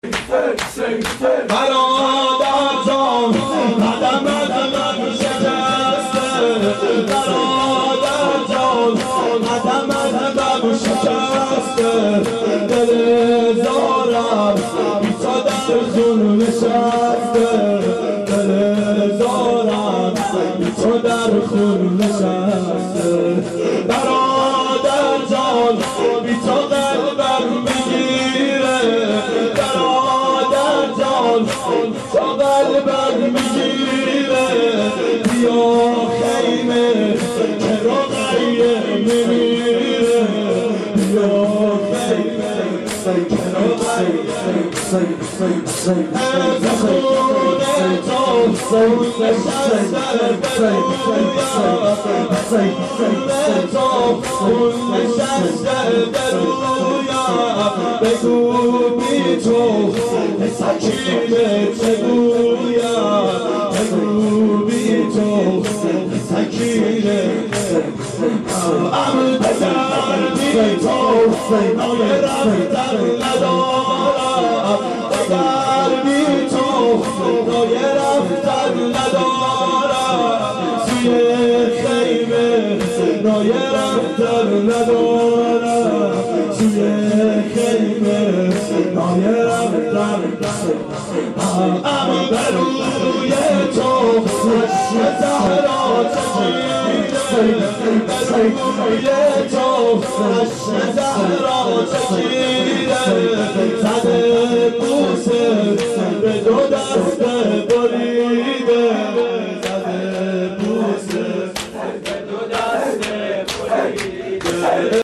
شب عاشورا 1389 هیئت عاشقان اباالفضل علیه السلام